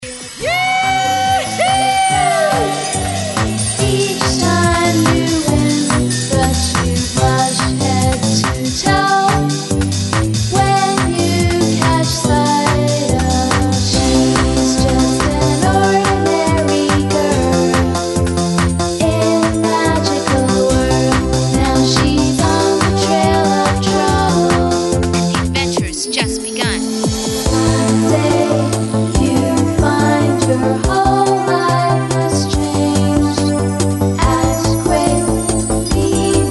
The quality is pretty good, so listen to 'em!